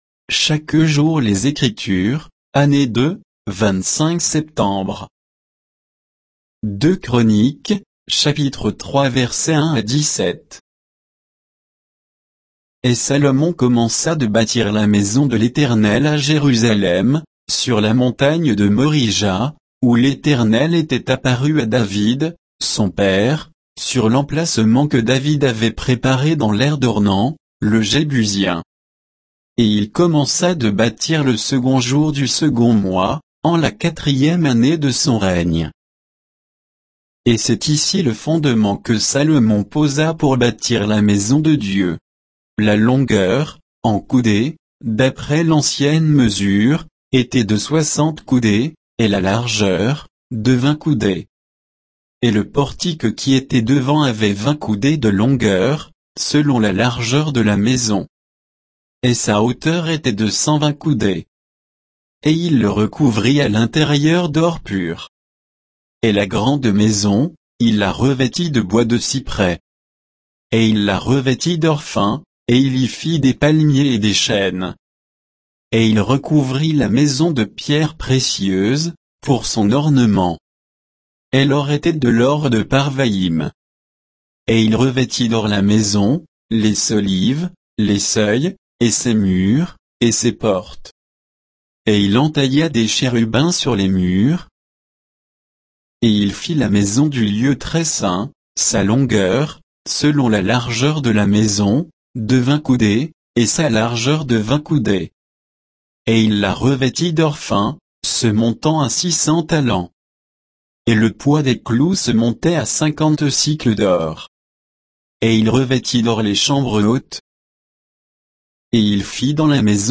Méditation quoditienne de Chaque jour les Écritures sur 2 Chroniques 3